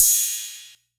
Cymbal.wav